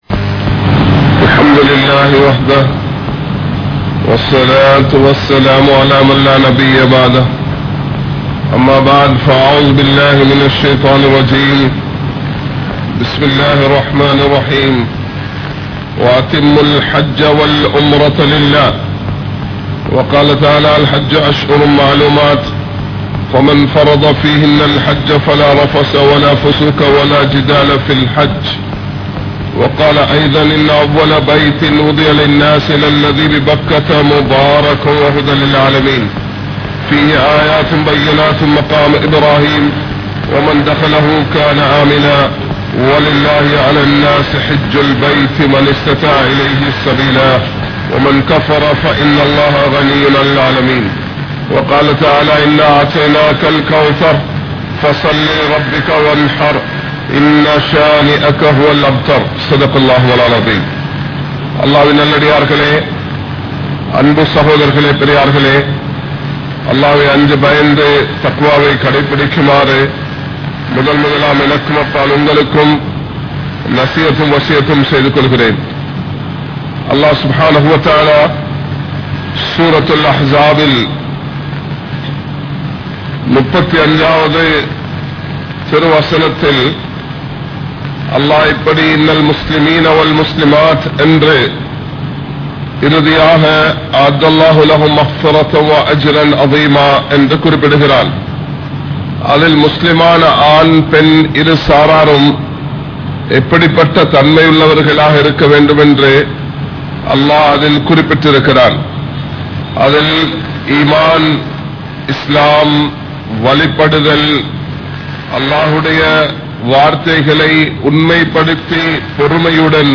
Haajihale! Ungalukku Oru Arivurai (ஹாஜிகளே! உங்களுக்கு ஒரு அறிவுரை) | Audio Bayans | All Ceylon Muslim Youth Community | Addalaichenai
Colombo 03, Kollupitty Jumua Masjith